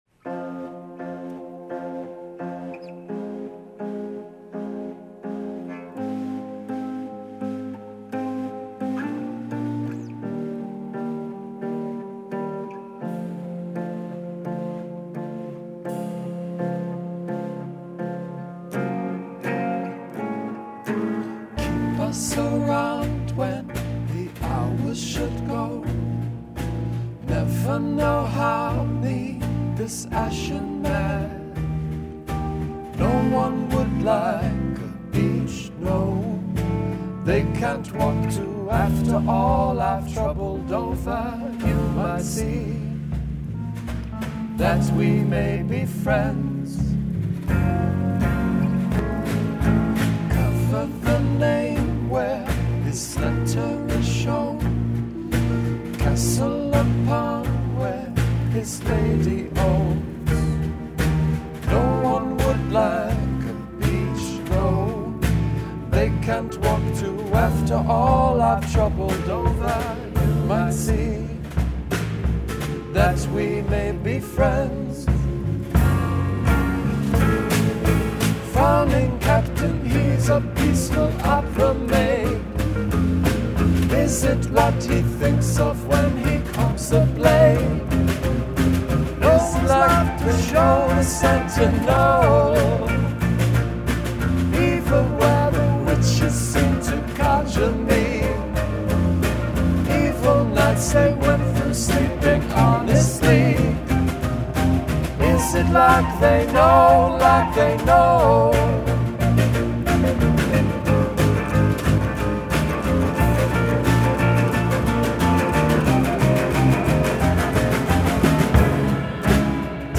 500 Temple Street, Detroit